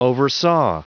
Prononciation du mot oversaw en anglais (fichier audio)
Prononciation du mot : oversaw